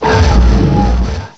cry_not_golurk.aif